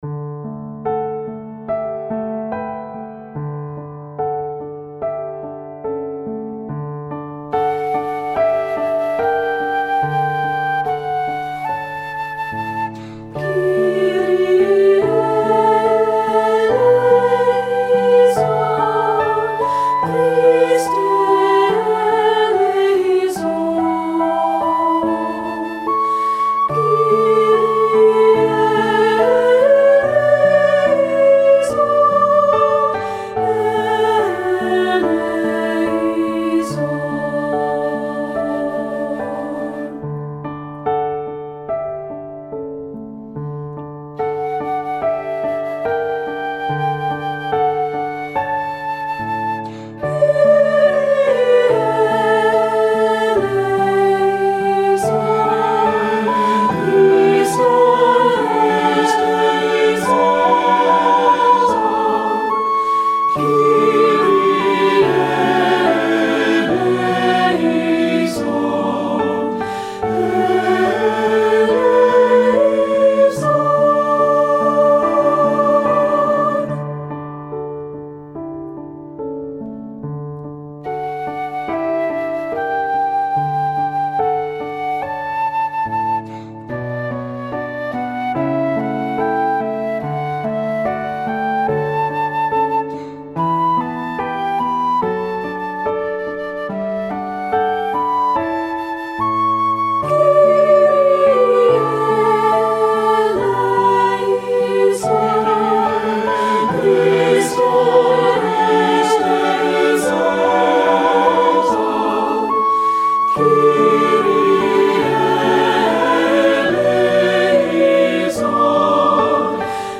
• Voice 1 (Part)
• Flute
• Piano
Studio Recording
Ensemble: Three-part Mixed Chorus
Key: D major
Accompanied: Accompanied Chorus